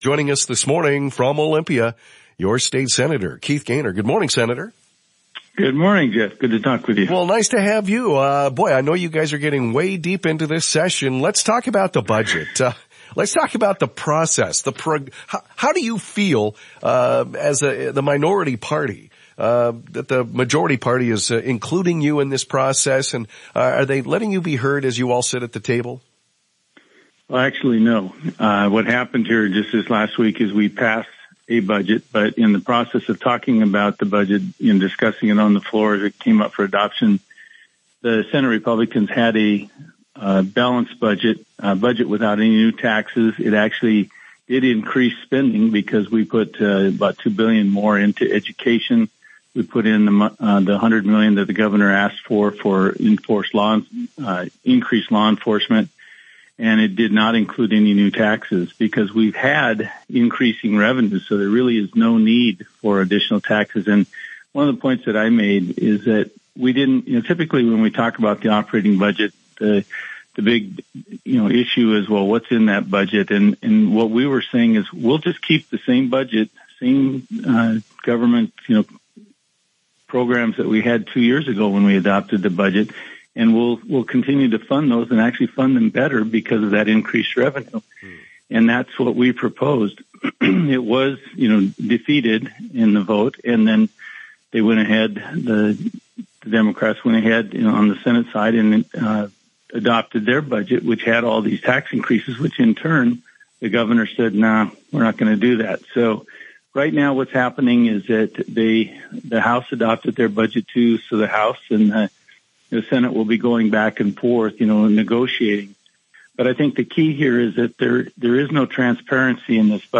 AUDIO: Senator Keith Goehner Addresses Budget, Housing, and Transparency Issues in KOZI Interview - Senate Republican Caucus